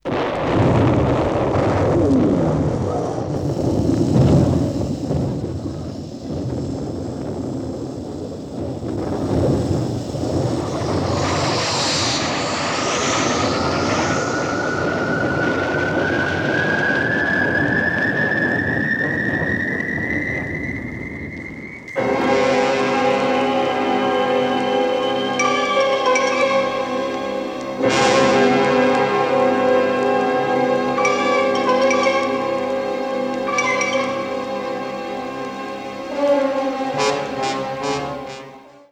In Sprong in het heelal serie 1 werd er nog niet door de ‘startende raket en aansluitende muziek‘ heen gesproken.